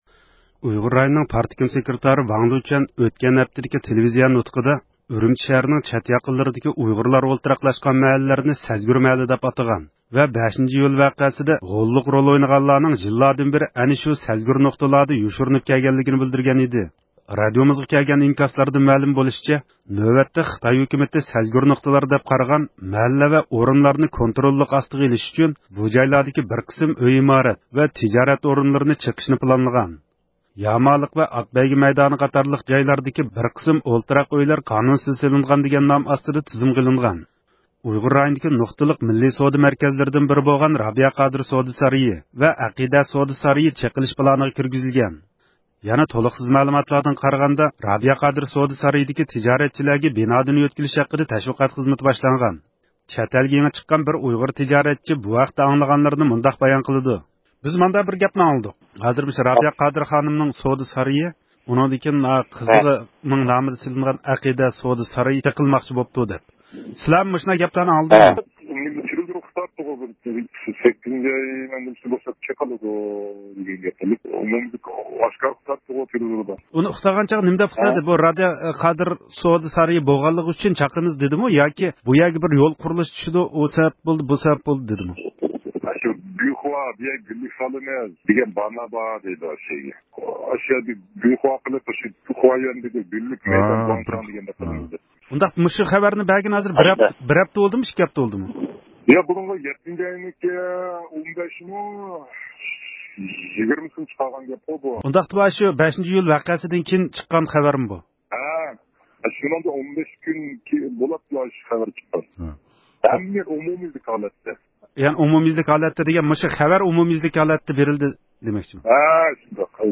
چەتئەلگە يېڭى چىققان بىر نەپەر ئۇيغۇر تىجارەتچى بۇ ھەقتە بىزگە مەلۇمات بەردى.
بۈگۈن بىز يەنە، مەزكۇر ساراينىڭ چېقىلىش خەۋىرى ھەققىدە رابىيە قادىر خانىم بىلەن سۆھبەت ئۆتكۈزدۇق.